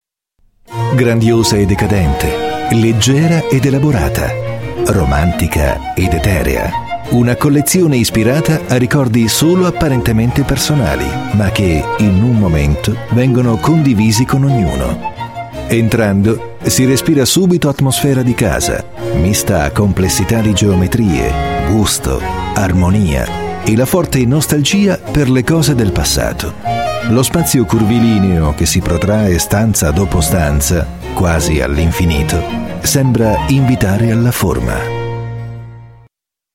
Speaker italiano madrelingua, voce calda e professionale, rassicurante e convincente.
Sprechprobe: Sonstiges (Muttersprache):
My voice is deep and warm, reassuring and convincing, ideal for narrations, commercials, smooth and professional for documentaries, multimedia project dvds, and many more.